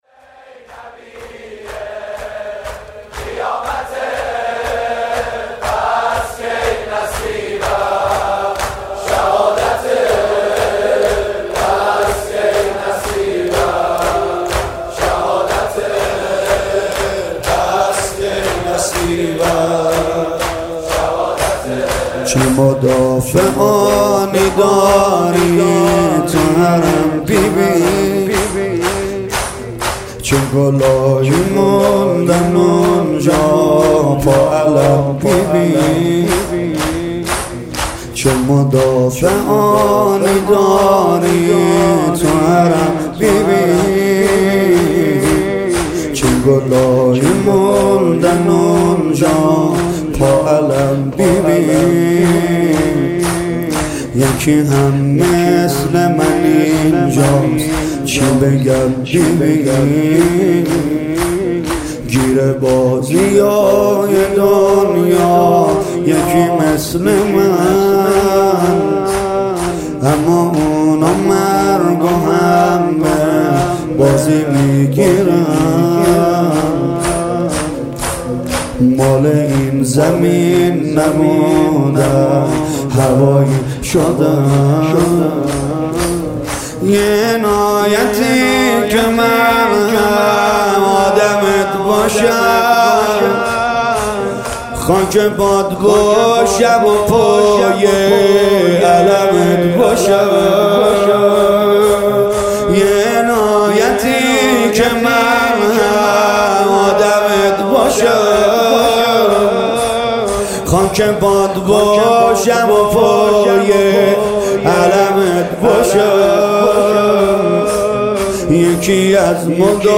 26 اسفند 95 - فدائيان حسين - شور - تو زینبیه قیامته